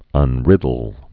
(ŭn-rĭdl)